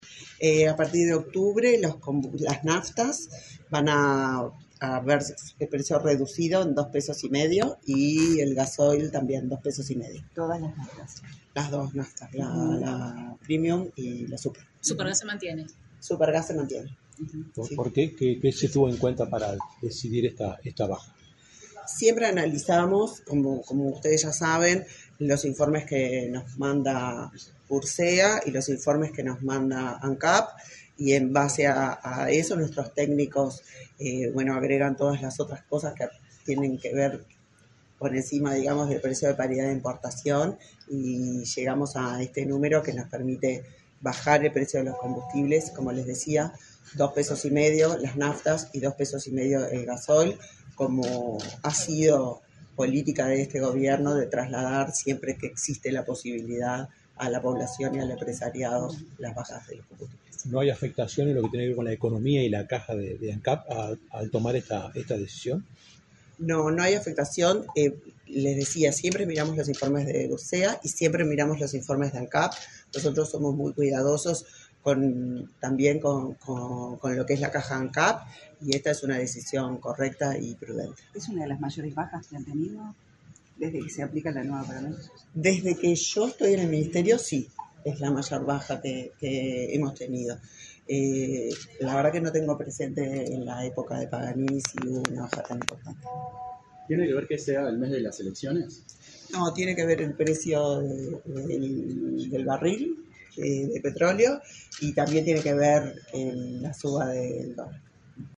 Declaraciones de la ministra de Industria, Elisa Facio